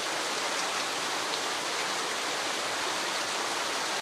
rain3.ogg